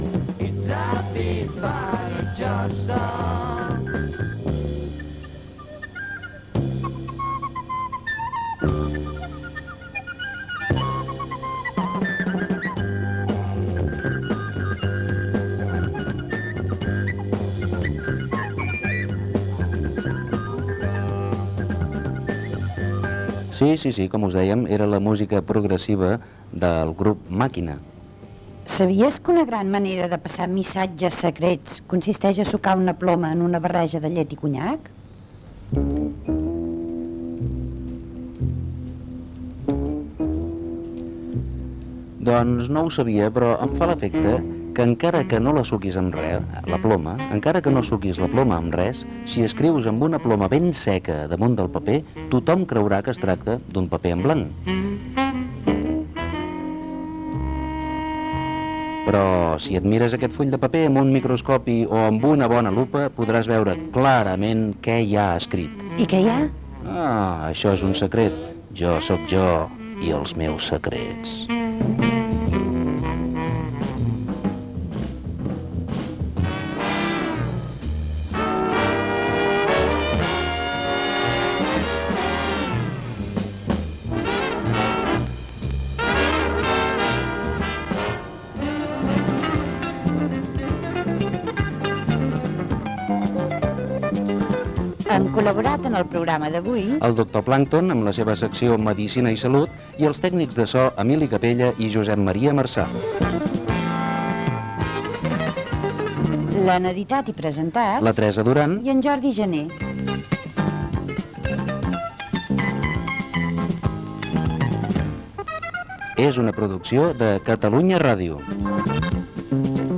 Tema musical, comentari sobre l'escriptura secreta, equip del programa, comiat i indicatiu de l'emissora
Infantil-juvenil